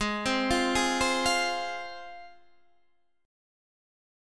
Listen to G#6 strummed